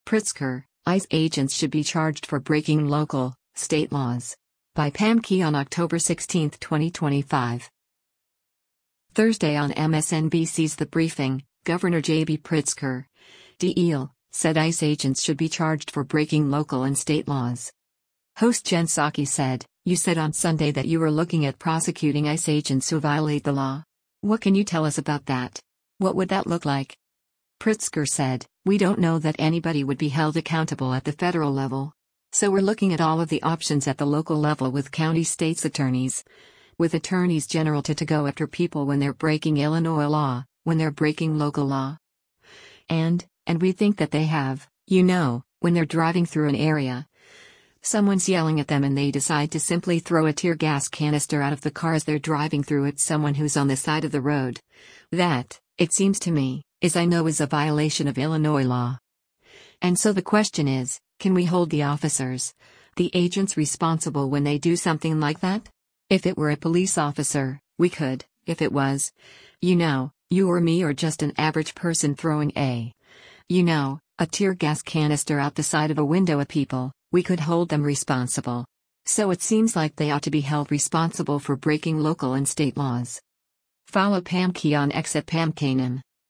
Thursday on MSNBC’s “The Briefing,” Gov. JB Pritzker (D-IL) said ICE agents should be charged for breaking local and state laws.